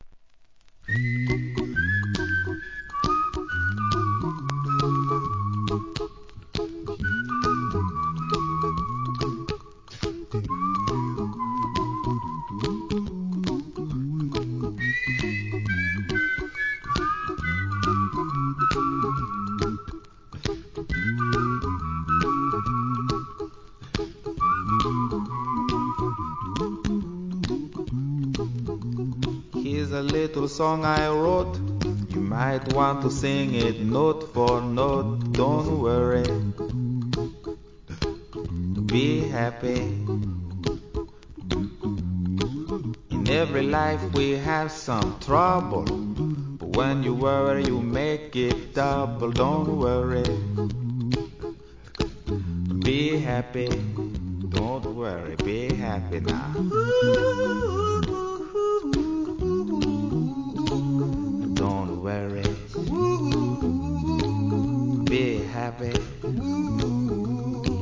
¥ 880 税込 関連カテゴリ SOUL/FUNK/etc...
US口笛のイントロから心を癒してくれる1988年名曲!!